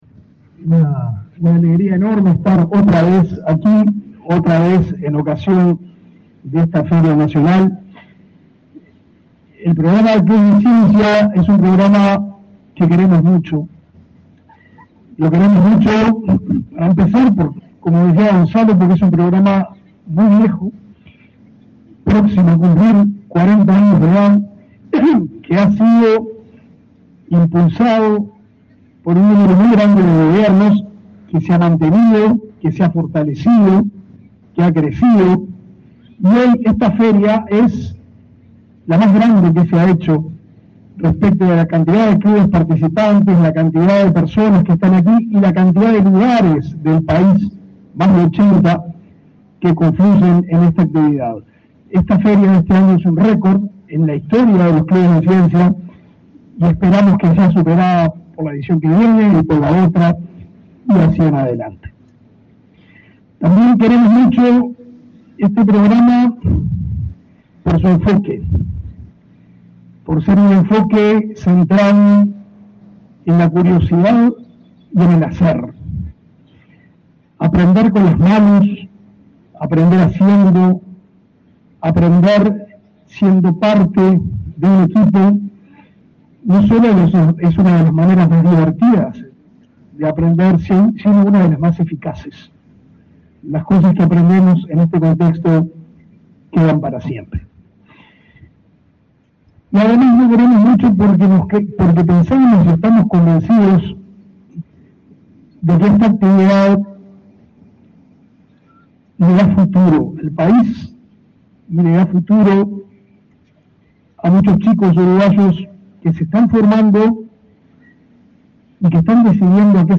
Palabras del ministro de Educación y Cultura, Pablo da Silveira
Palabras del ministro de Educación y Cultura, Pablo da Silveira 16/11/2023 Compartir Facebook X Copiar enlace WhatsApp LinkedIn La 37.ª edición de la Feria Nacional de Clubes de Ciencia comenzó este 16 de noviembre y se extenderá hasta el 18, en la cuidad de Piriápolis, en el departamento de Maldonado. En el evento, organizado por el Ministerio de Educación y Cultura, disertó el ministro Pablo da Silveira.